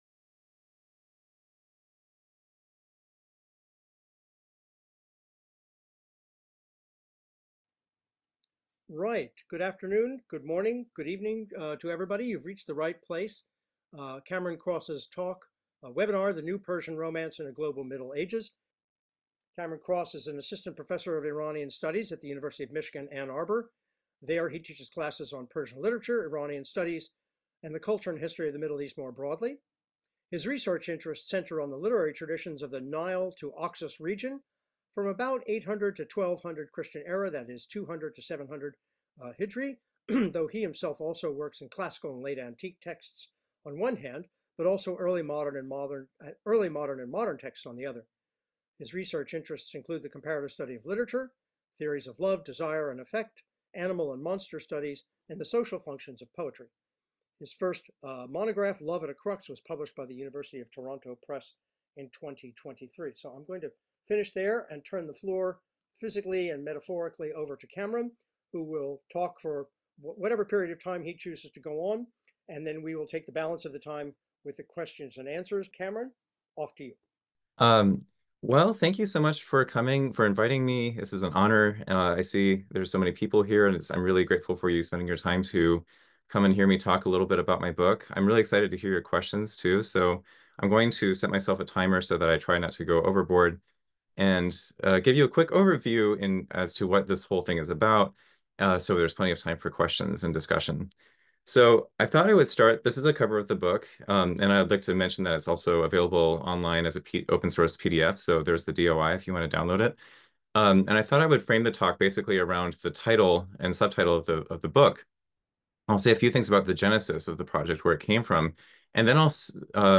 With a focus on the remarkable story of Vis & Ramin, this talk seeks to situate that event in the broader context of the entangled literary histories of southwestern Afro-Eurasia from late antiquity to the medieval period, showing how the Persian corpus plays a crucial role in the history of romance writing at large.